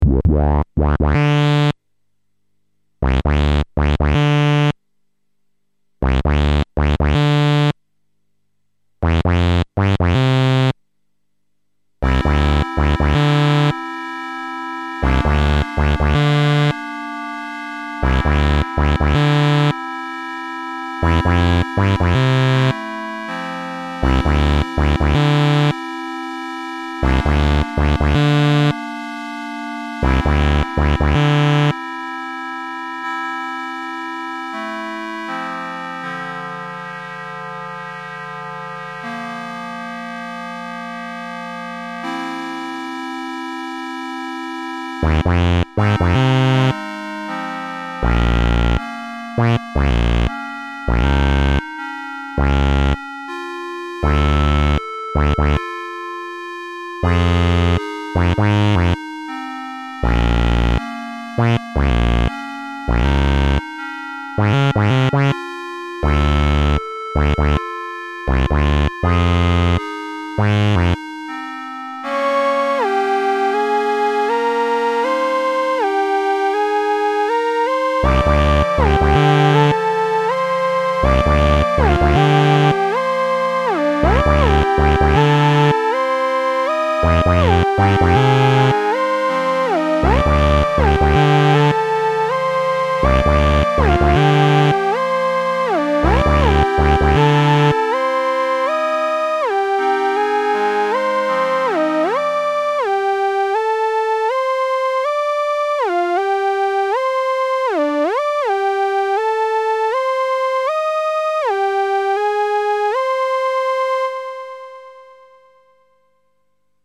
Il s'agit d'une petite track avec une basse, un pad et un lead.Les sonorités sont très simples, la composition est rapide et épurée.
sequence 1 - nord modular G2
je me rend compte que la fréquence de coupure de la basse doit pas être bien réglée...
utilisation du detune sur le pad.
enregistré en stéréo via une table behringer, dans une carte son moyenne (game theater xp) 44khz 16 bit,
en une seule prise, aucun traitement sauf normalisation.
BDG1_nordmodularG2.mp3